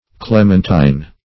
Clementine \Clem"ent*ine\, a.